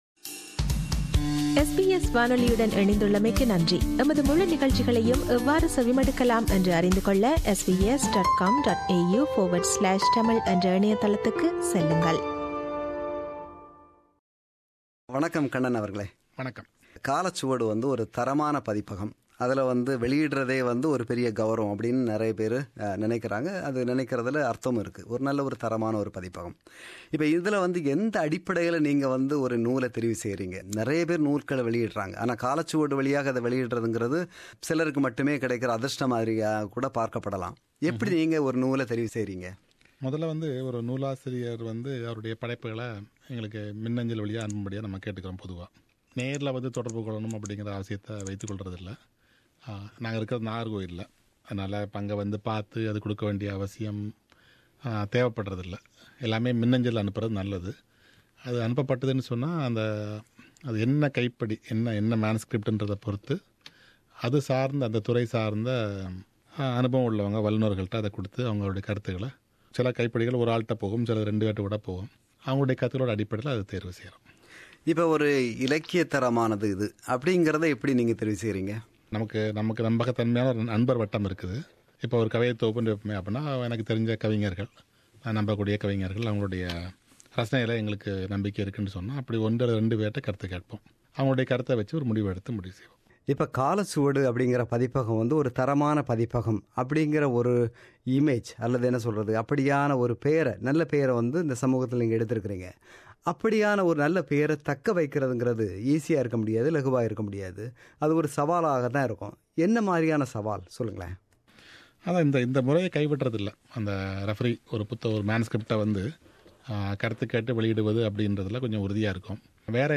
SBS ஒலிப்பதிவு கூடத்தில் சந்தித்து உரையாடியவர்